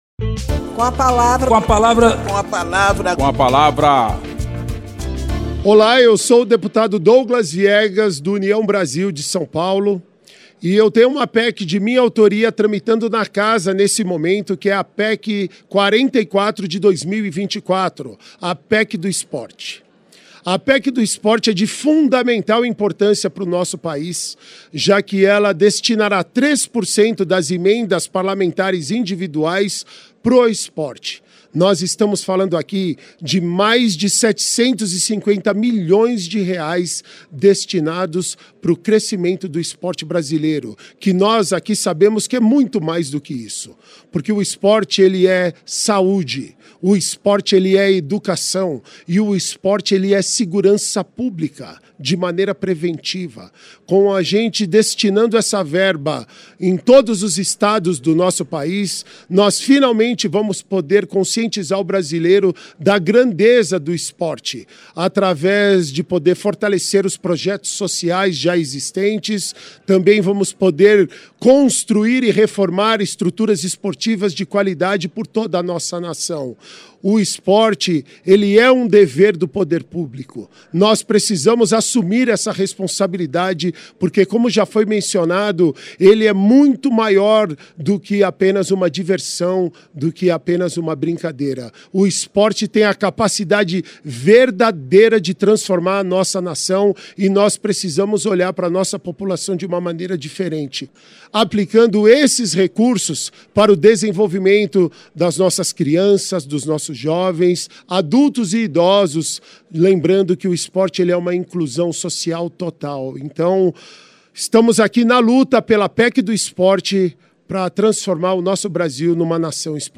O deputado Douglas Viegas (União-SP) ressalta a importância da Proposta de Emenda à Constituição (PEC 44/2024), que apresentou para destinar 3% das emendas parlamentares individuais a programas e ações esportivas.
Espaço aberto para que cada parlamentar apresente aos ouvintes suas propostas legislativas